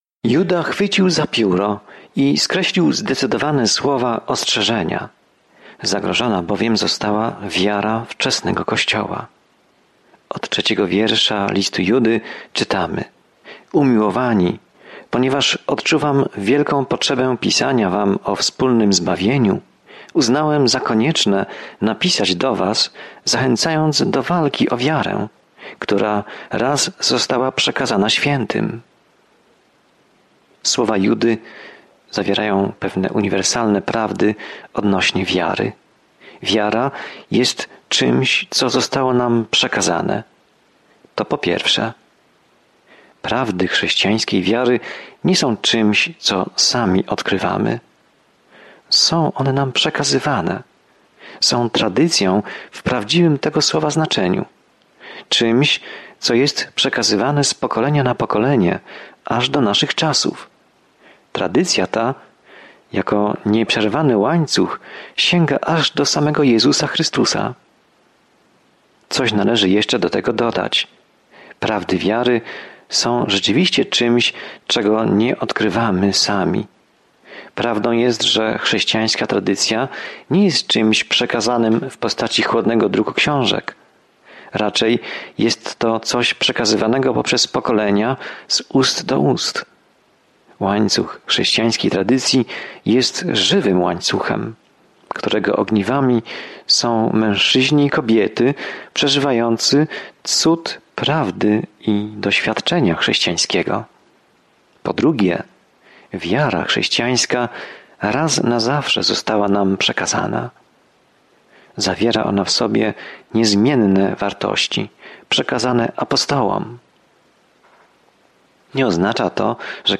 Pismo Święte Judy 1:4-5 Dzień 2 Rozpocznij ten plan Dzień 4 O tym planie „Walcz o wiarę” – głosi ten krótki, ale bezpośredni list Judy do chrześcijan walczących z fałszywymi nauczycielami, którzy niezauważeni wśliznęli się do kościoła. Codziennie podróżuj przez Judę, słuchając studium audio i czytając wybrane wersety słowa Bożego.